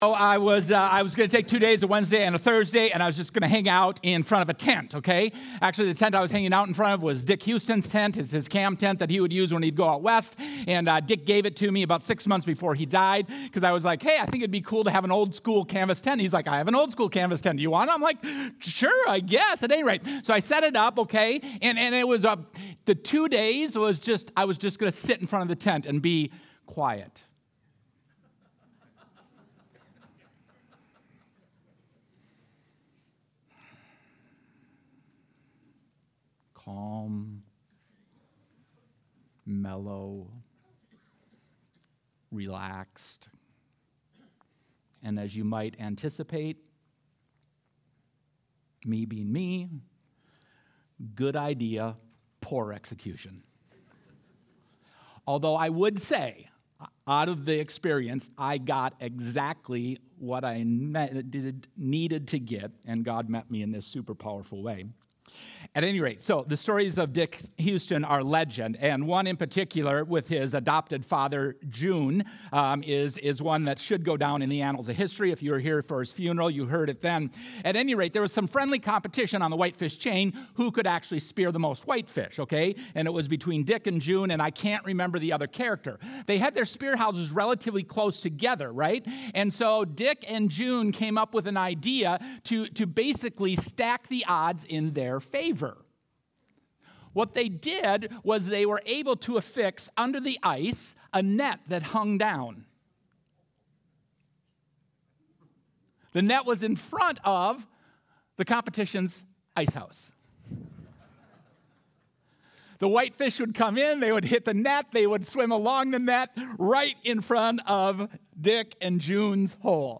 Sunday Sermon: 6-29-25